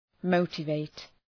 Shkrimi fonetik {‘məʋtə,veıt}